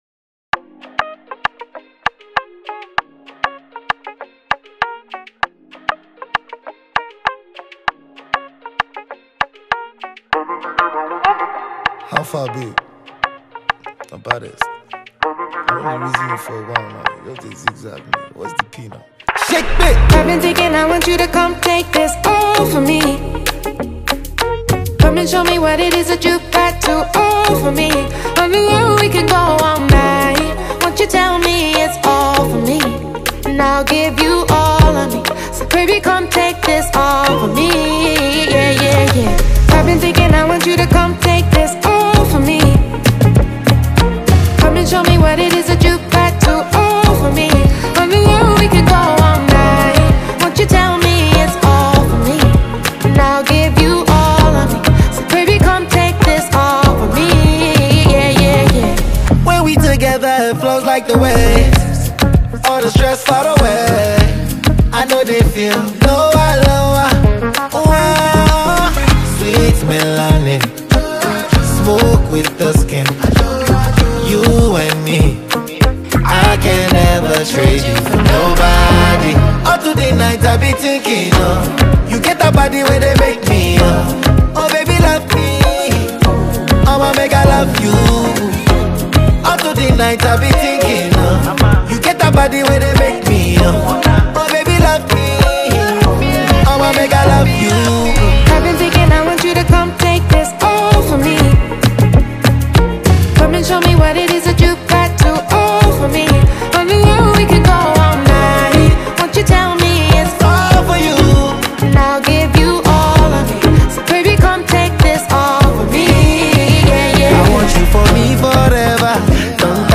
Get this energizing song